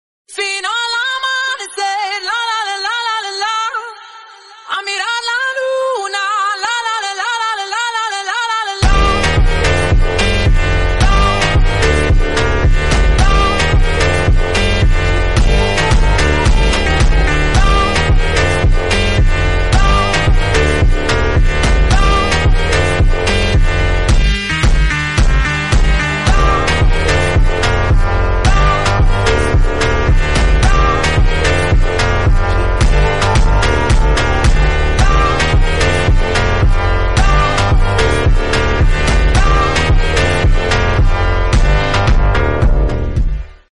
Atmospheric Latin Phonk Beat